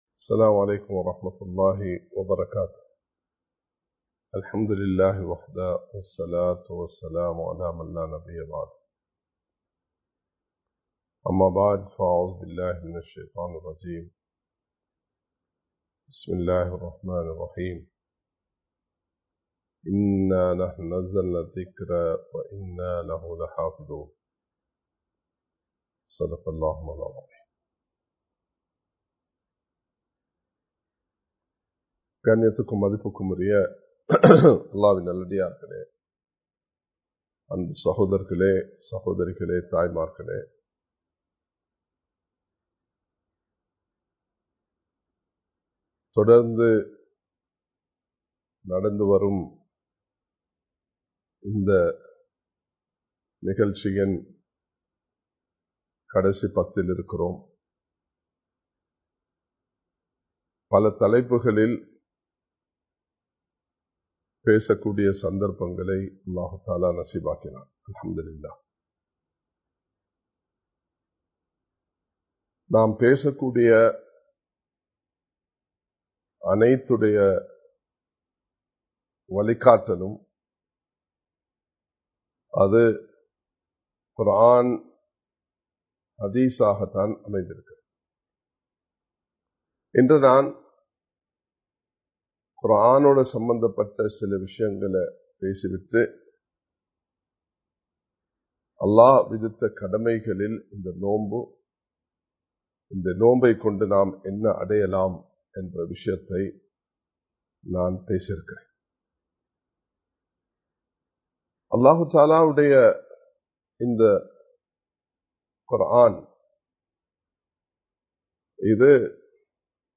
அல்குர்ஆனும் நாமும் (The Holy Quran and We) | Audio Bayans | All Ceylon Muslim Youth Community | Addalaichenai
Live Stream